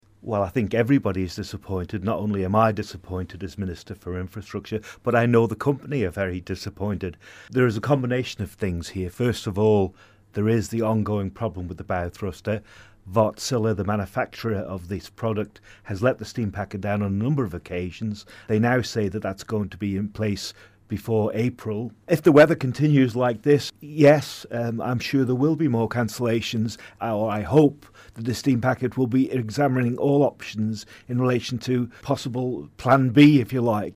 The Infrastructure Minister has admitted to 3FM his own frustration at a series of Steam Packet cancellations.